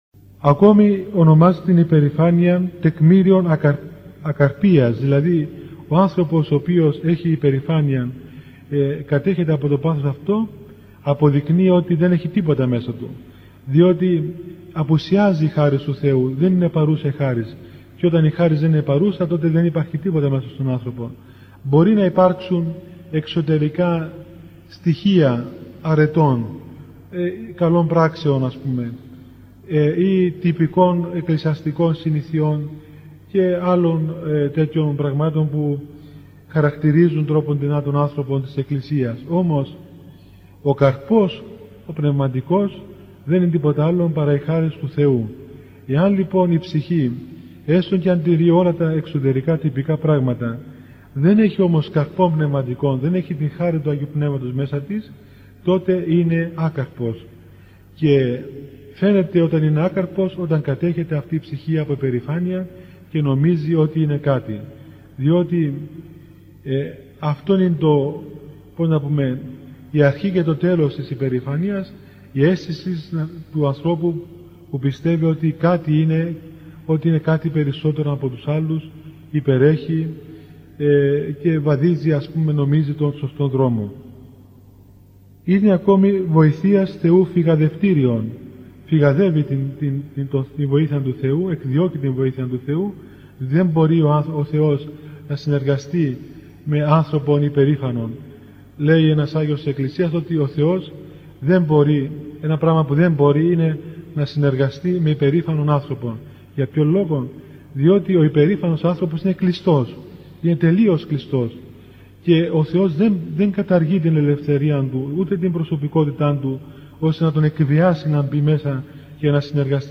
Μία πολλὰ ψυχωφέλιμη ὁμιλία τοῦ Πανιερωτάτου Μητροπολίτου Λεμεσοῦ κ. Ἀθανασίου.